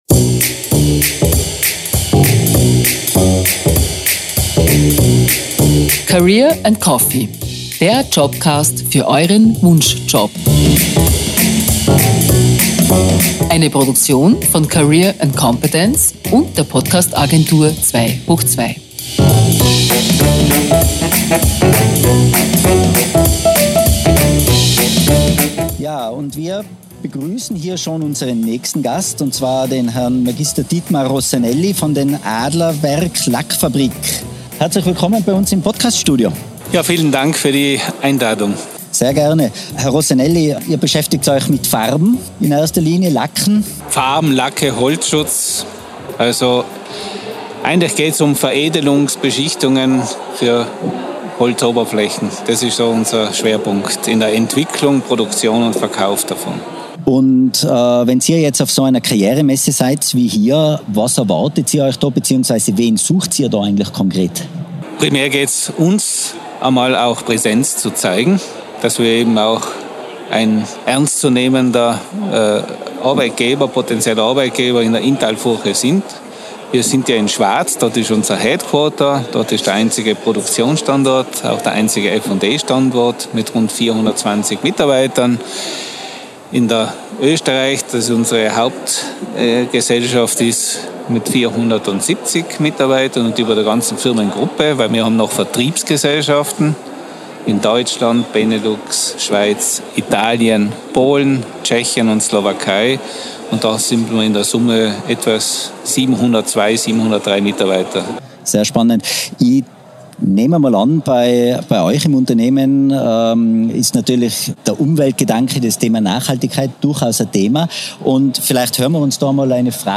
Livemitschnitt von der Career & Competence-Messe mit
Masterlounge in Innsbruck am 4. Mai 2022.